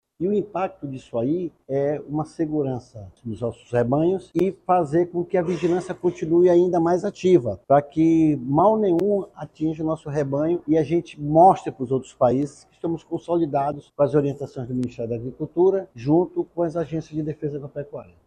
A medida estabelece ações coordenadas entre os três estados e reforça a vigilância sanitária na região, como explica o diretor-presidente da Adaf, José Omena.